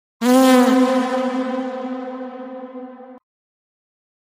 Âm thanh Farting of mic, meme đánh rắm vang dội
Thể loại: Âm thanh meme Việt Nam
Description: Âm thanh Farting of mic là một hiệu ứng hài hước phổ biến trong các meme internet và video ngắn. Âm thanh này mô phỏng tiếng đánh rắm vang dội, thường được chỉnh sửa để nghe to, méo tiếng hoặc dội âm như phát qua micro.
am-thanh-farting-of-mic-meme-danh-ram-vang-doi-www_tiengdong_com.mp3